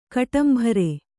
♪ kaṭambhare